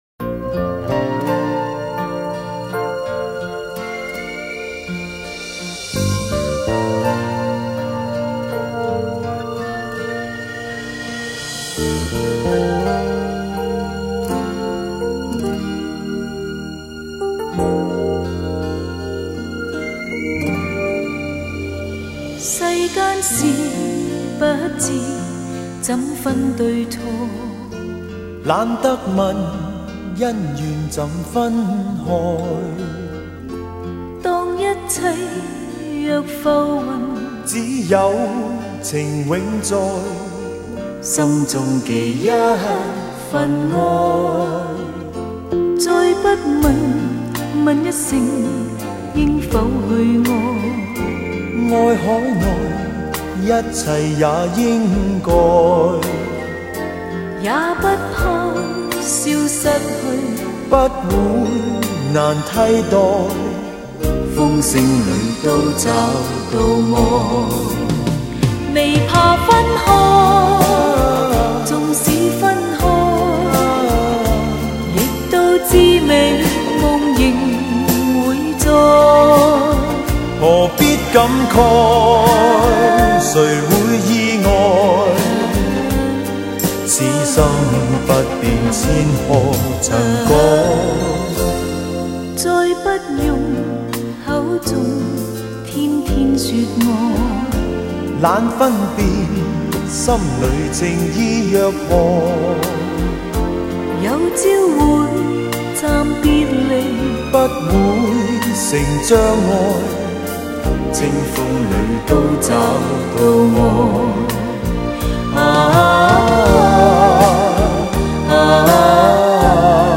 清纯的声音 清新的感觉 优美的和声